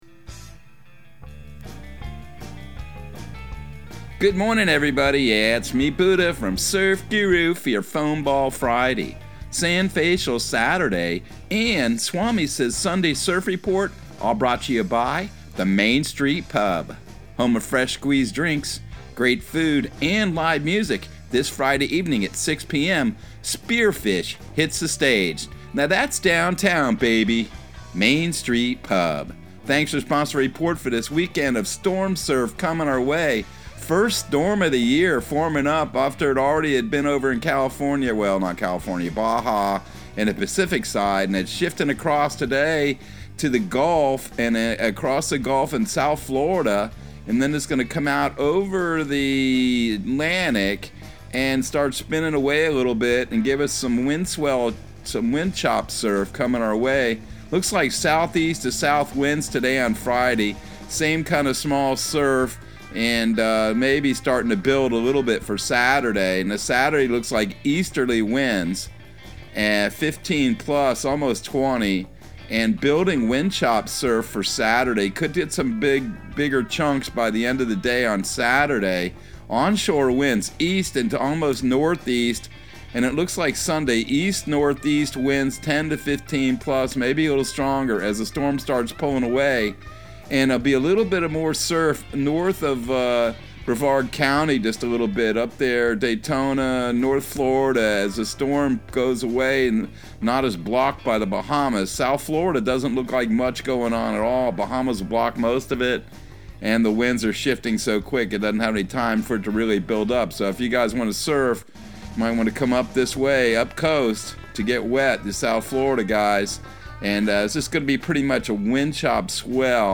Surf Guru Surf Report and Forecast 06/03/2022 Audio surf report and surf forecast on June 03 for Central Florida and the Southeast.